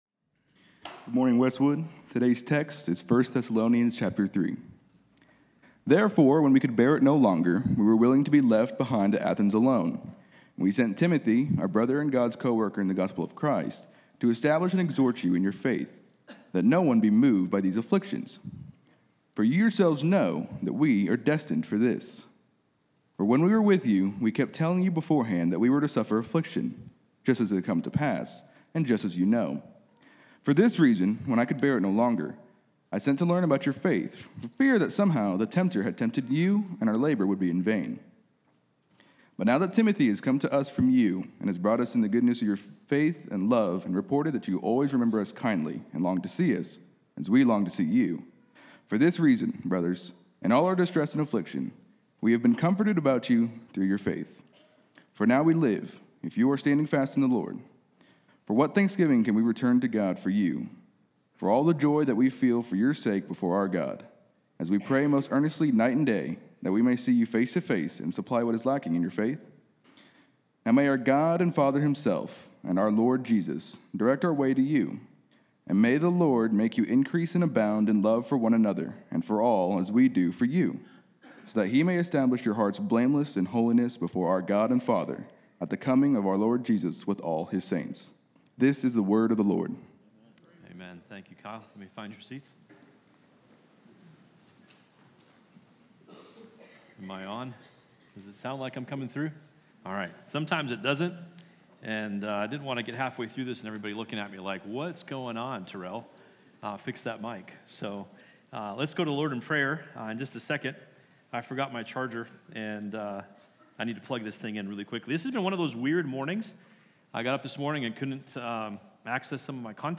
A message from the series "1 Thessalonians."